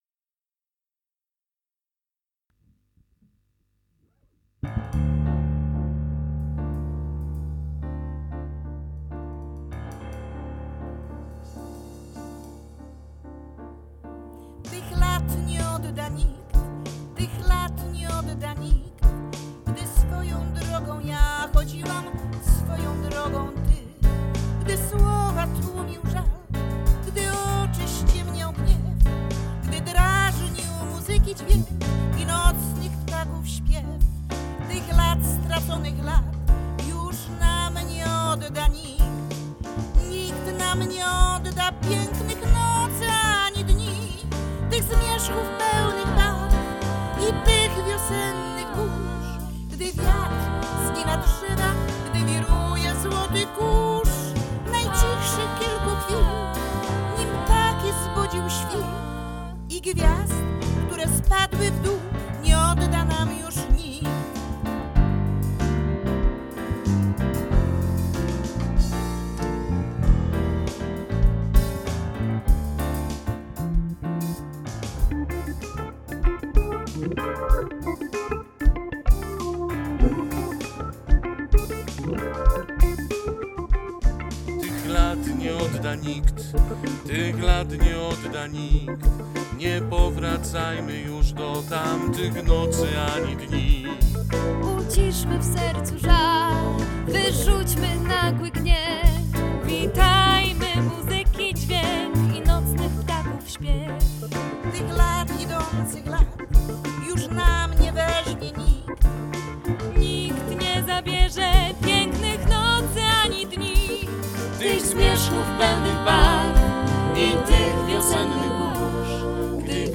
Po latach dla Państwa zaśpiewali: